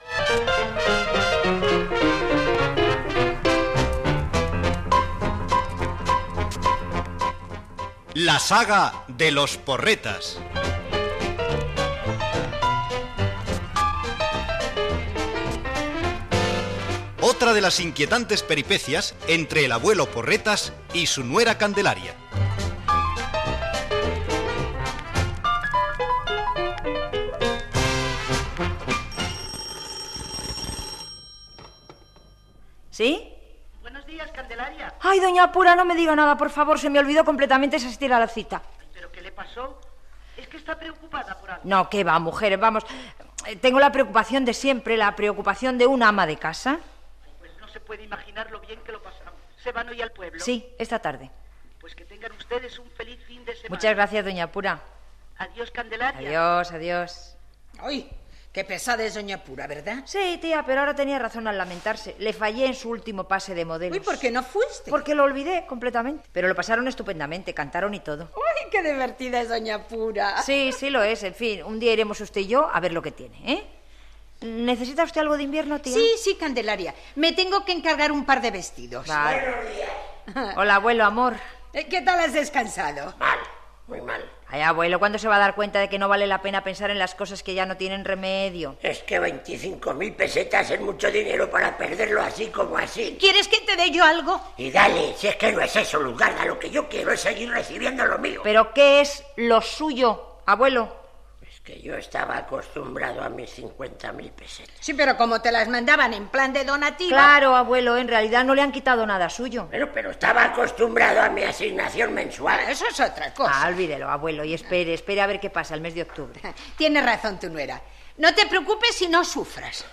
Careta del programa. Diàleg entre Candelaria i Doña Pura. Diàleg entre Candelaria, l'avi i la tia. Arriba el net Juanito que dialoga amb l'avi parlant de la reducció de l'assignació que rep del seu fill.
Ficció